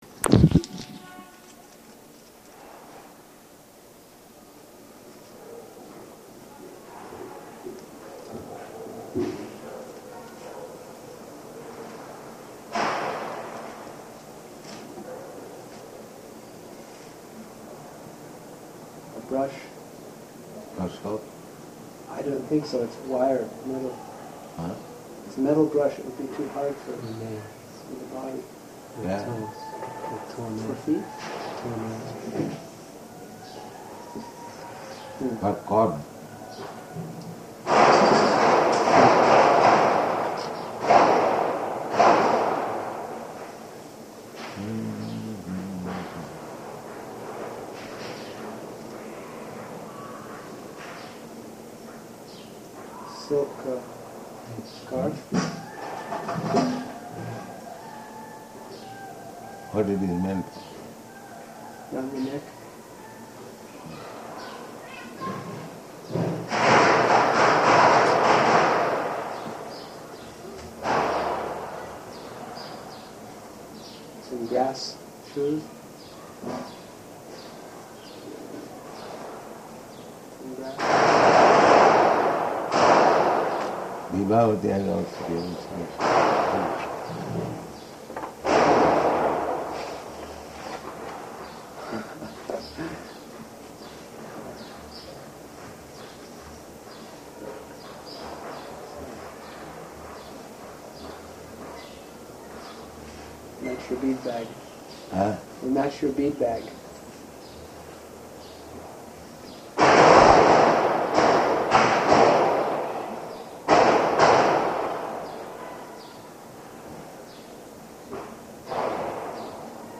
Room Conversation
Location: New York